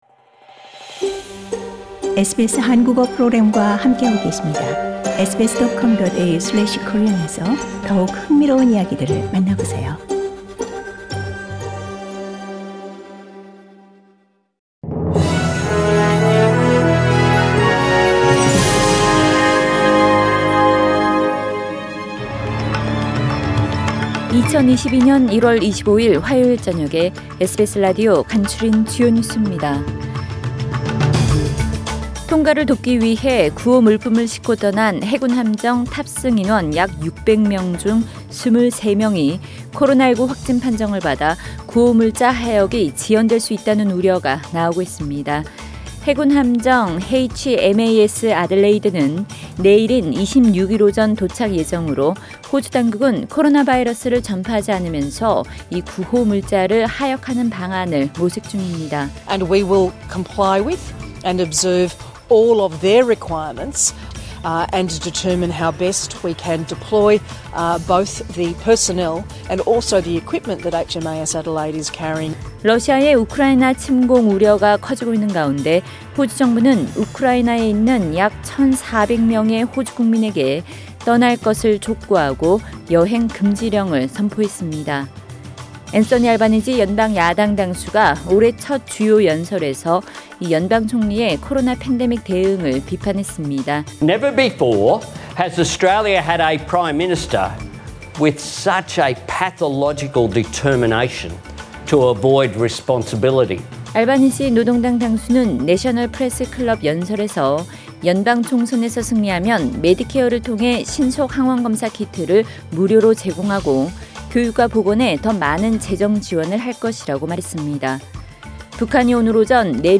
SBS News Outlines…2022년 1월 25일 저녁 주요 뉴스